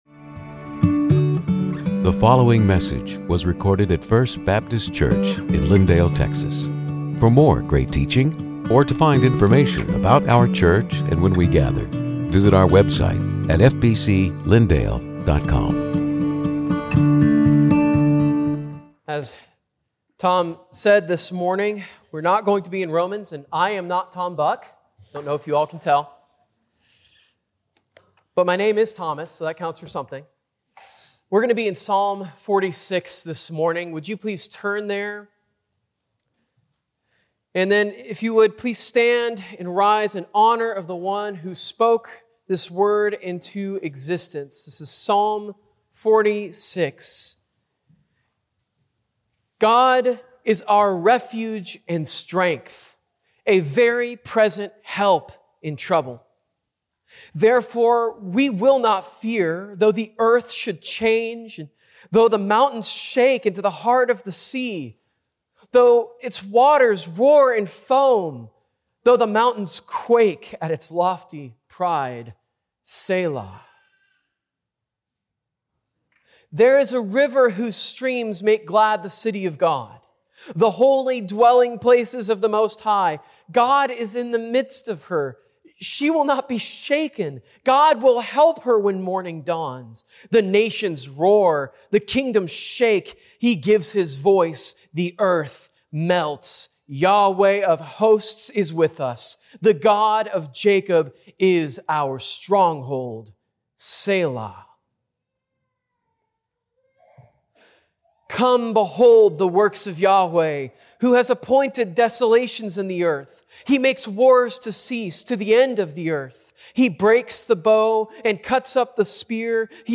Sermons › Psalm 46